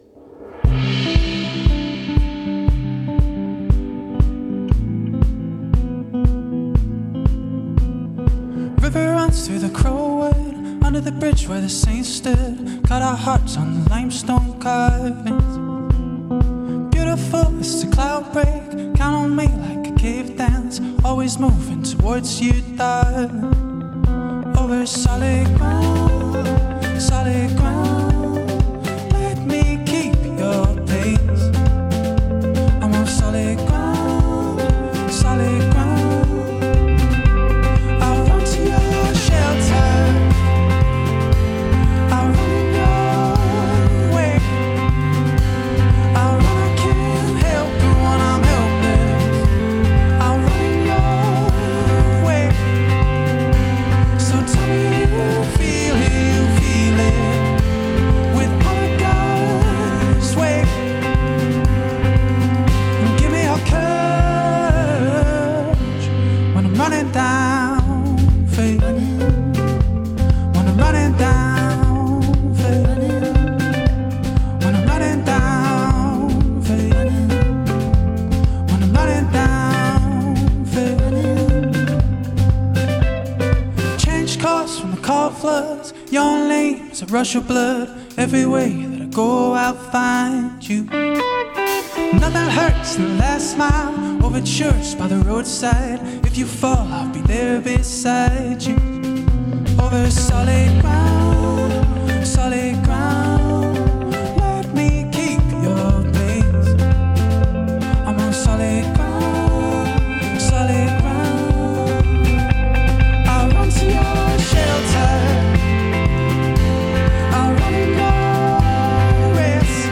Taking it down a few notches this Friday night